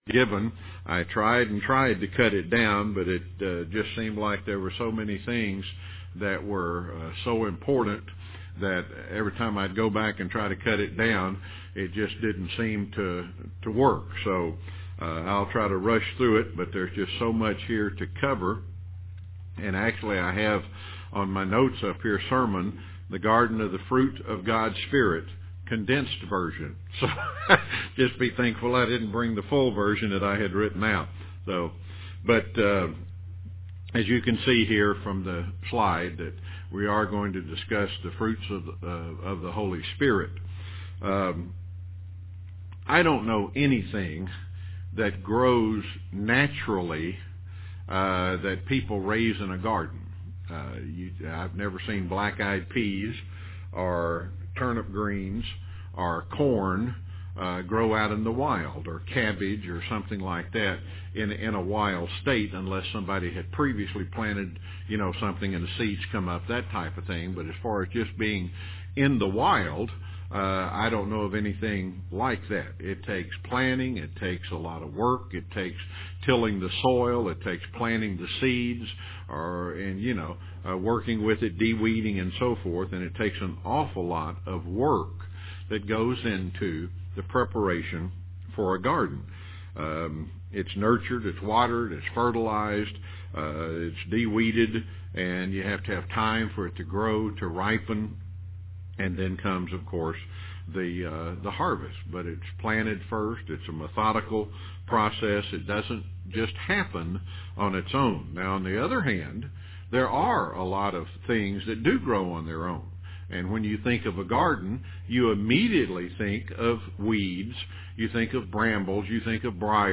This sermon examines each of the fruits of the Holy Spirit listed in Gal. 5